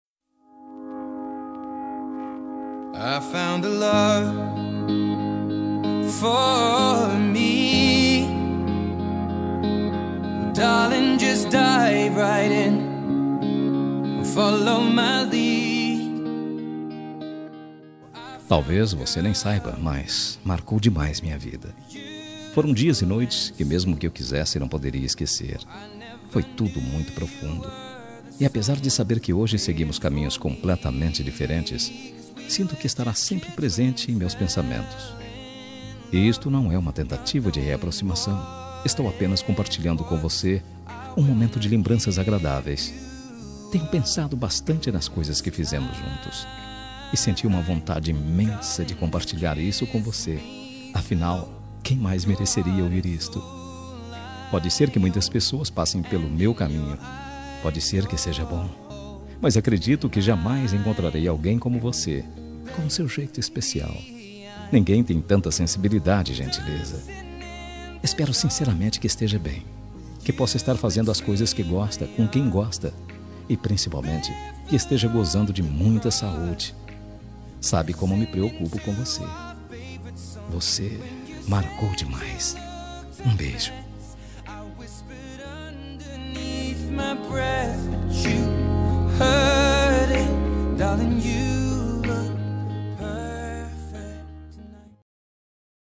Telemensagem Romântica Ex. – Voz Masculina – Cód: 6469